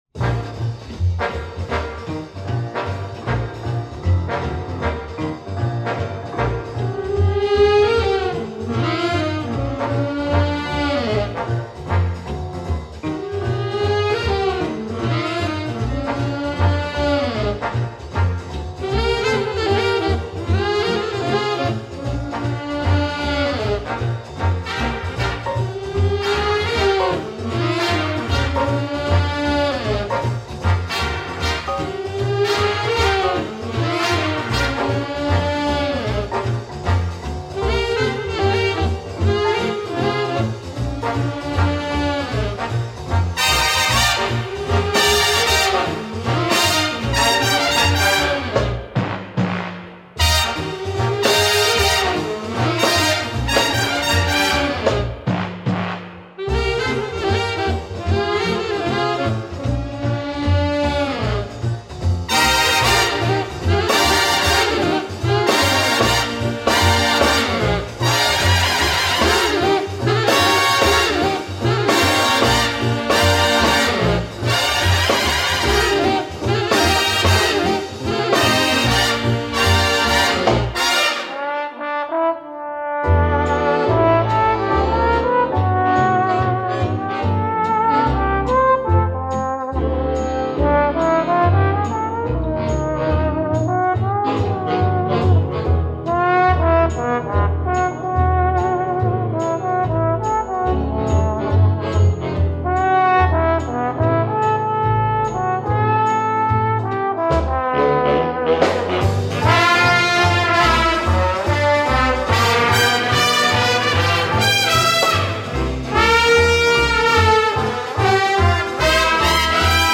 оркестра